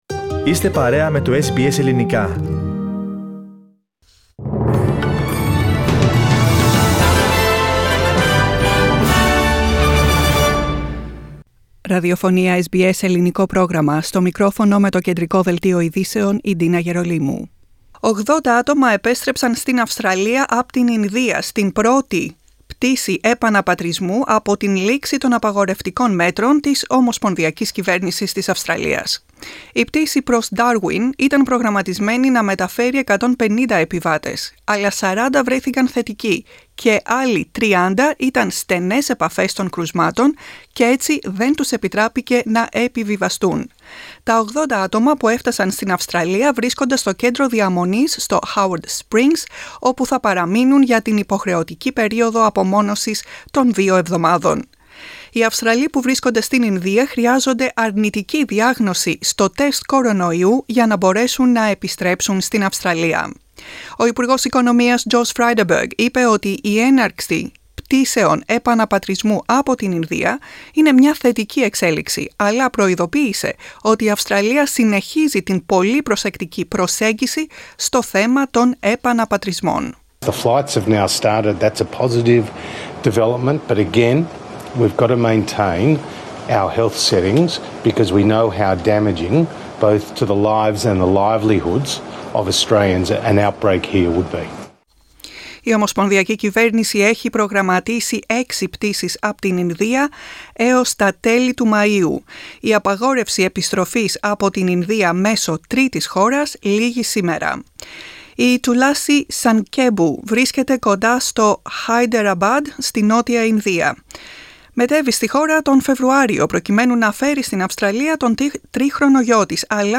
The main bulletin of the day.